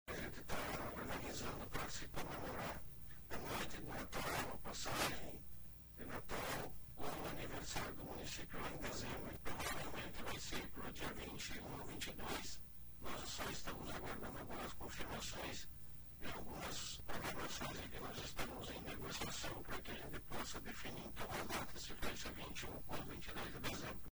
Ontem à noite, durante entrevista no programa de Bozano, pela RPI, o prefeito, Geder Mori, disse que nesse momento não haverá programação de aniversário, até por solicitação de representantes da comunidade, em razão da colheita da soja e dificuldade de receitas financeiras devido às últimas frustrações de safras por problemas climáticos. Porém, haverá atividades no final de ano. (Abaixo, sonora de Geder Mori)